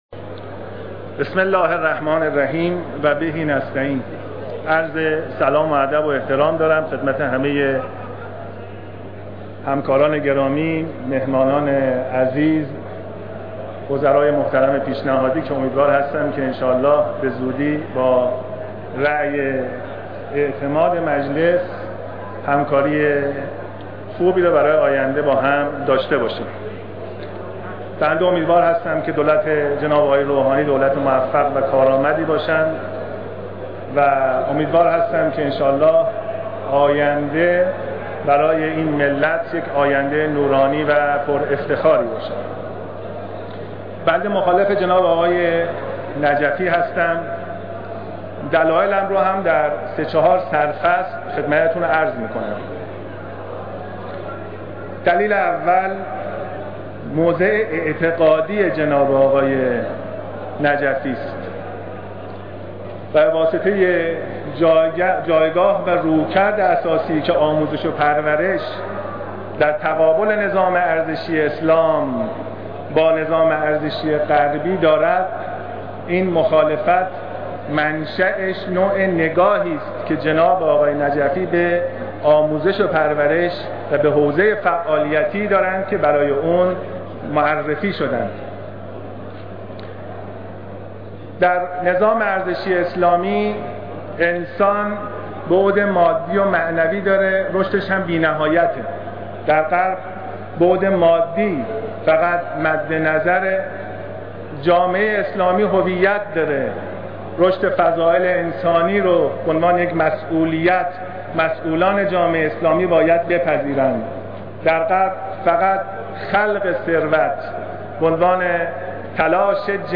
صوت/ نطق زاکانی درمخالفت با نجفی